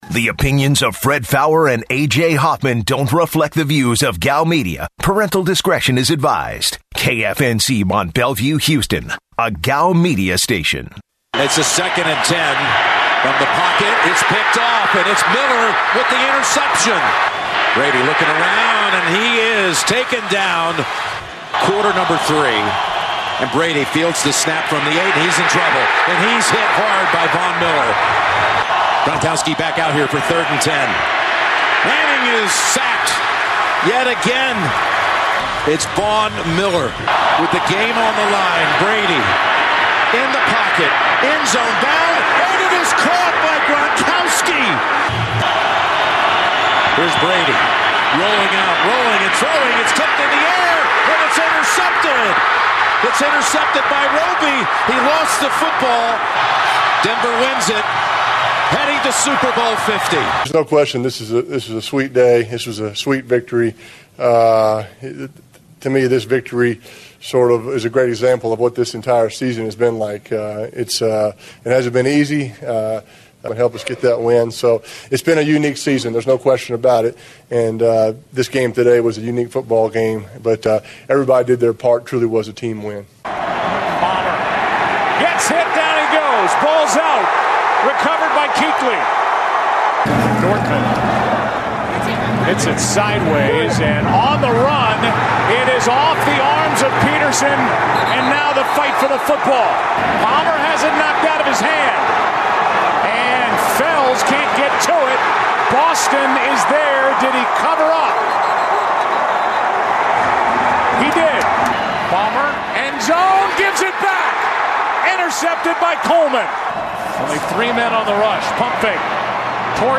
The guys take some calls about Super Bowl 50, and money line for the big game.